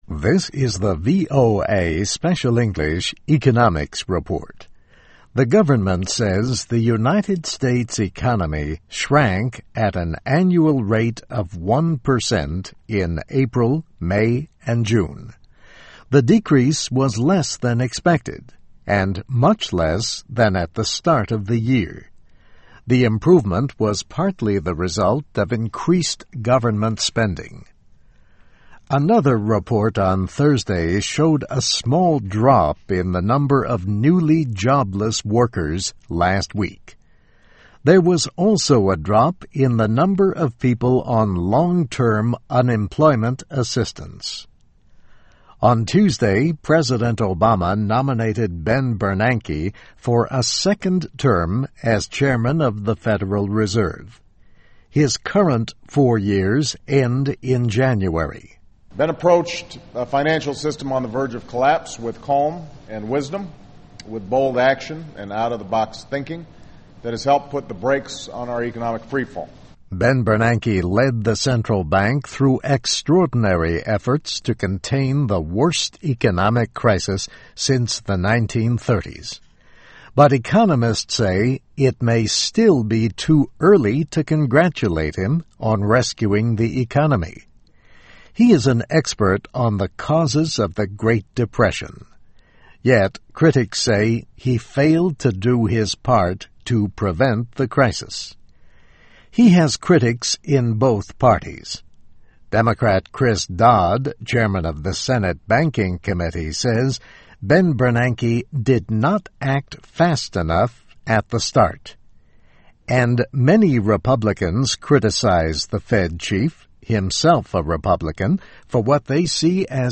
VOA Special English, Economics Report, Obama Nominates Bernanke for a Second Term